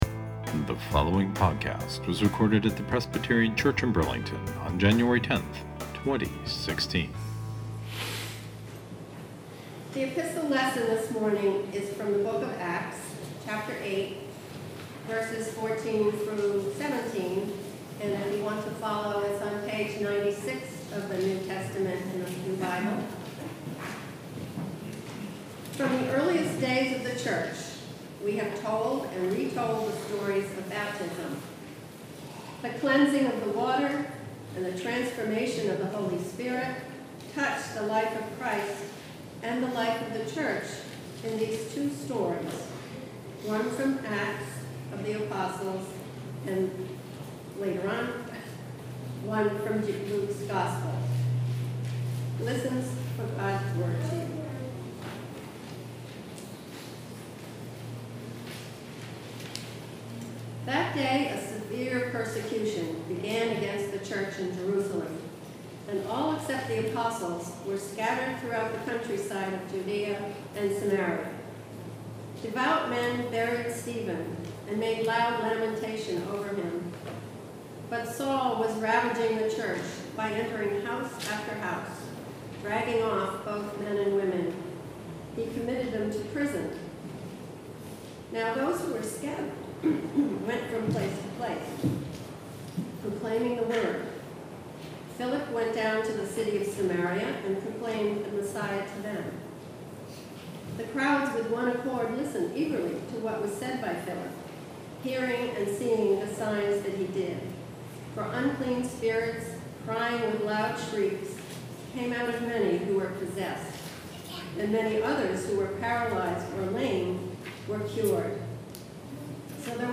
Another sermon from the back-log…